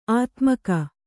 ♪ ātmaka